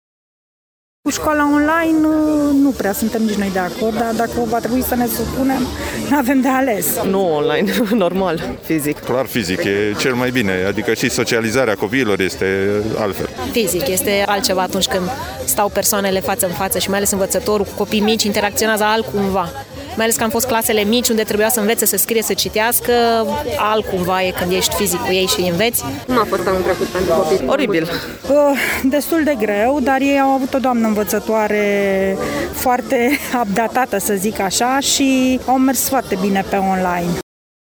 Școala a început cu prezența fizică, spre bucuria elevilor, dar şi a părinţilor care nu au fost foarte încântaţi de şcoala online:
vox-scoala-online.mp3